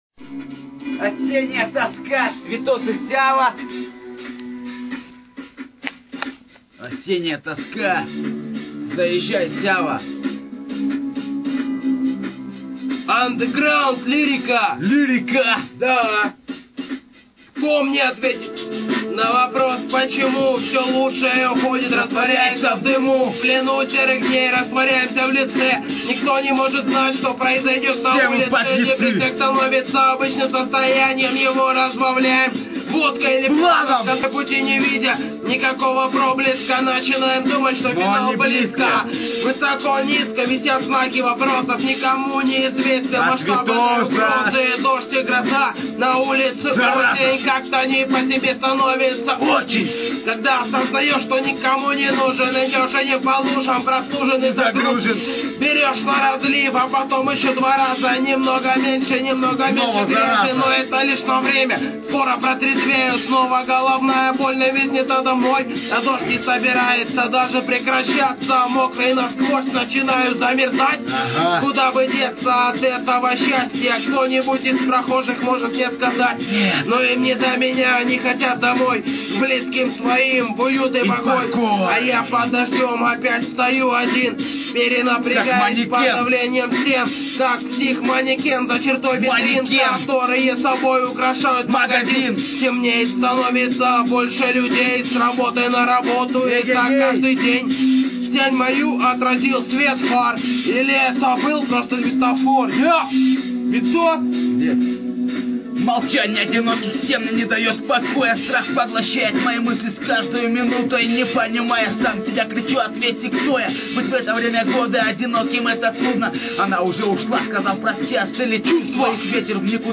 (Лайф)